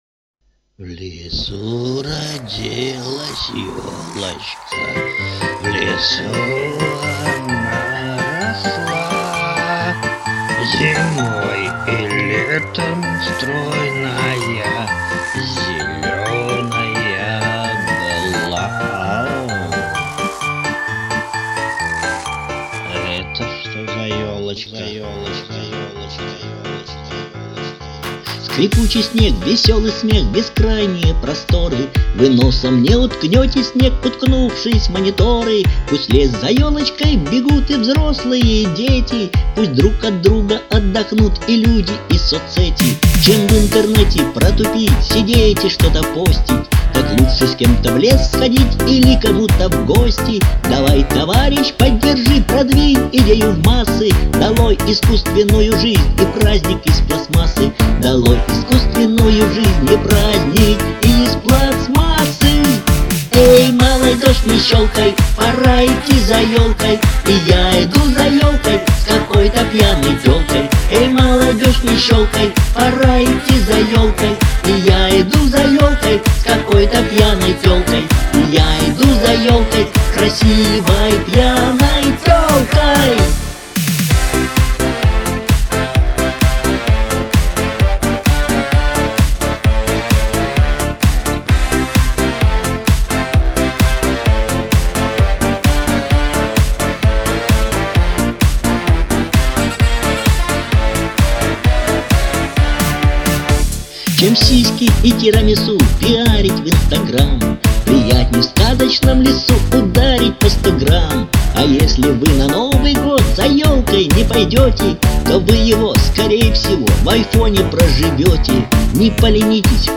Обе песенки очень позитивные, поднимают настроение!